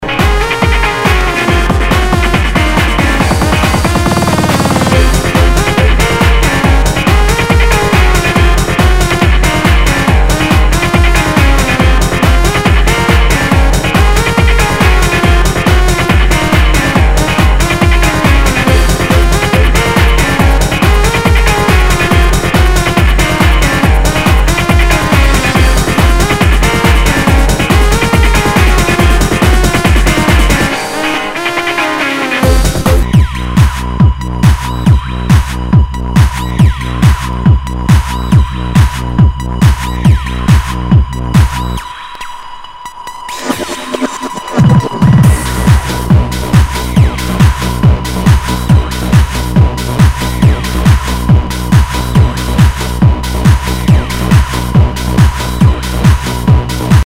HOUSE/TECHNO/ELECTRO
ハード・ハウス！